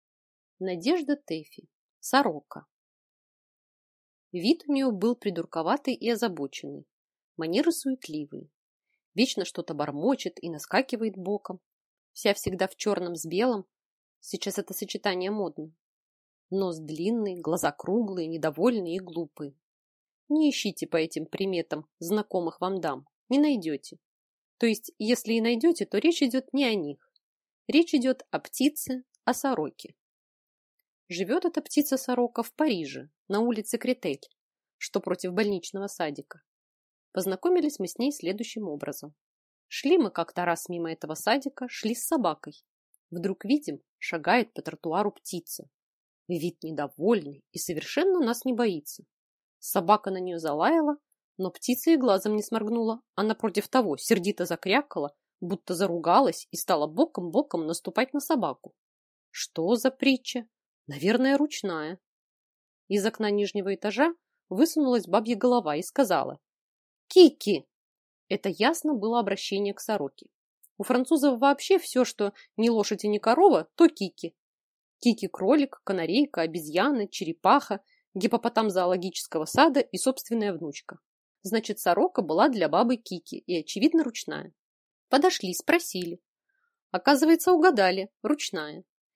Аудиокнига Сорока | Библиотека аудиокниг